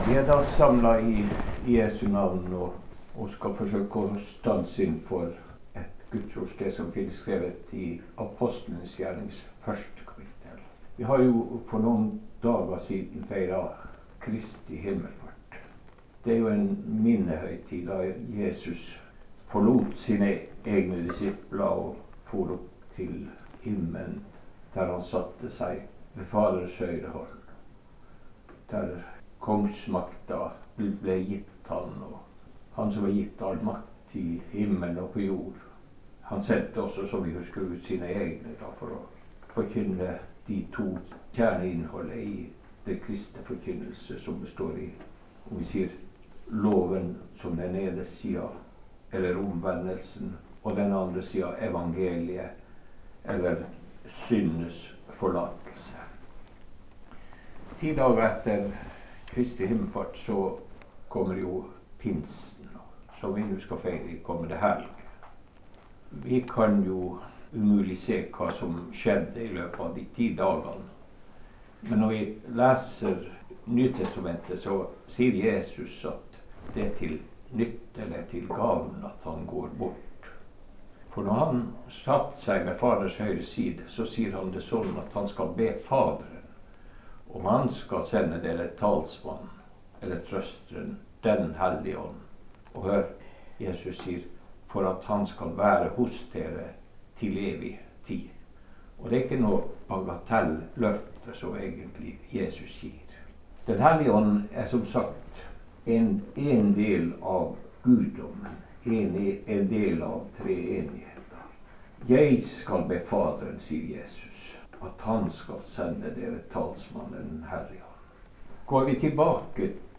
Andakt over Jesu himmelfart og løfte om hans gjenkomst